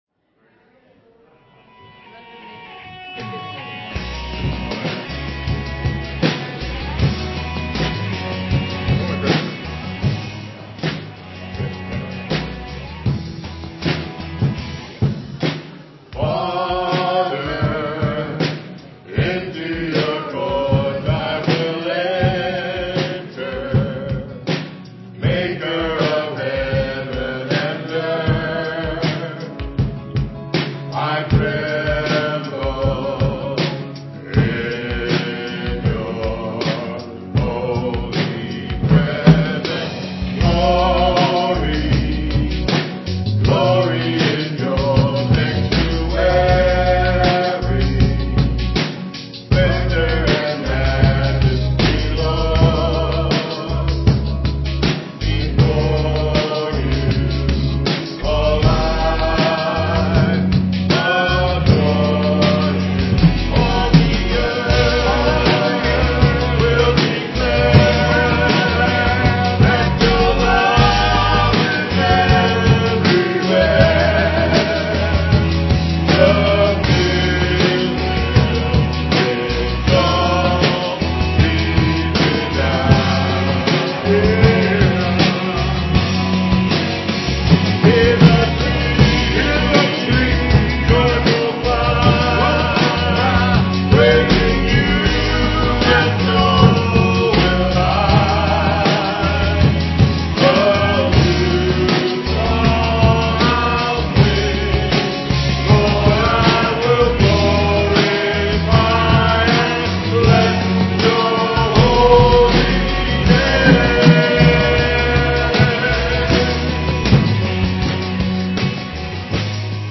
PLAY A Time for Remembrance, May 24, 2009 Memorial Day weekend.